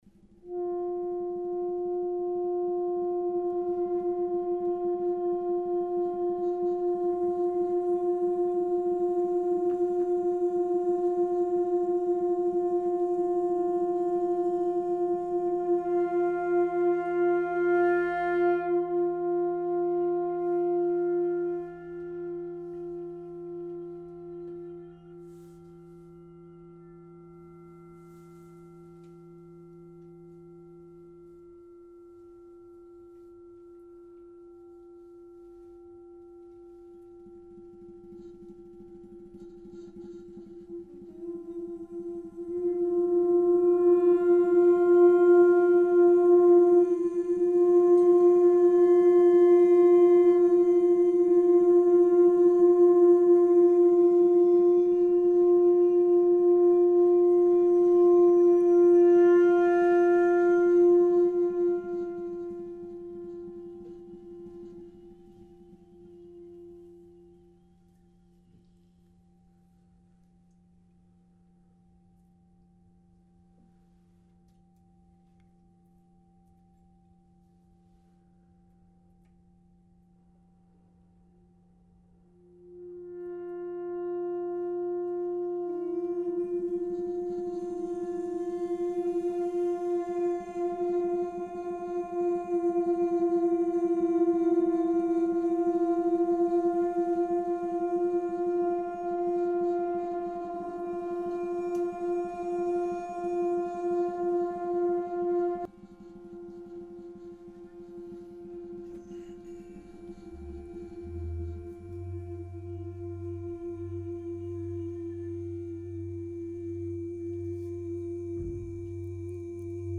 electronics
voice
trombone
piece of metal on snaredrum
flutes